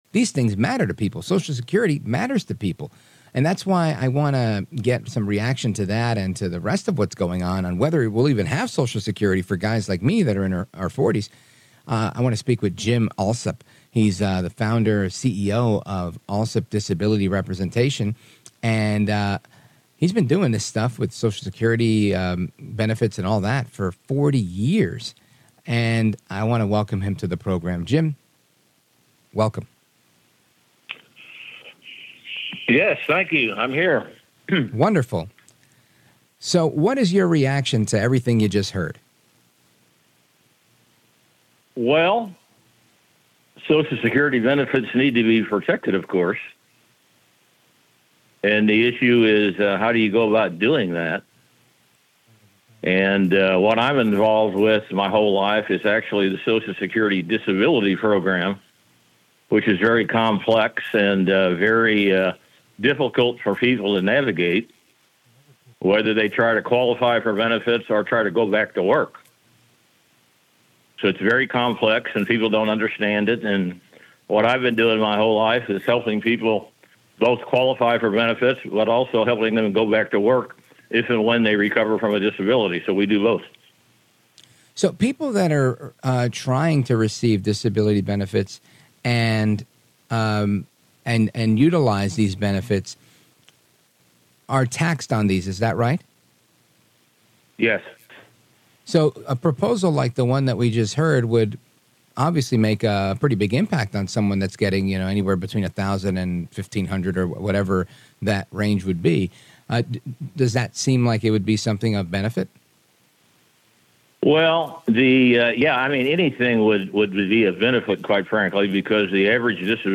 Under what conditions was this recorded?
Radio studio ready for an interview to be conducted.